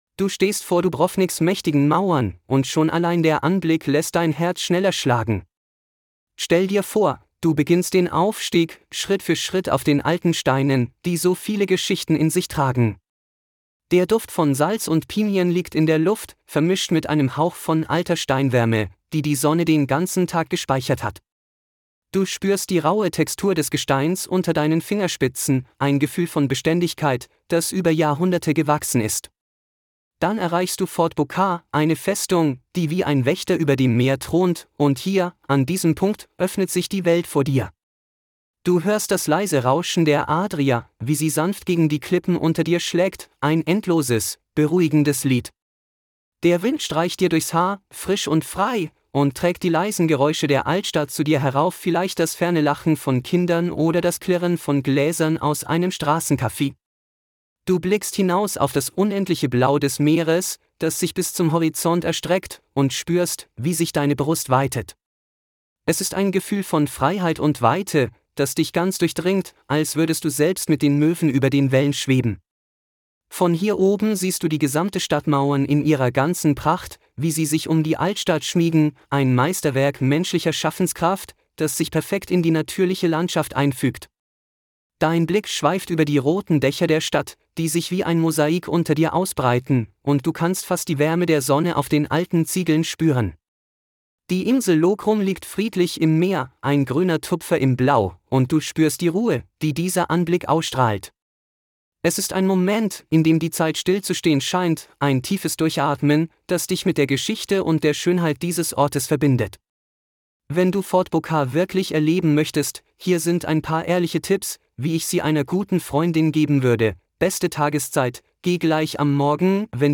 Fort Bokar Dubrovnik: Audioführer & lokale Geheimnisse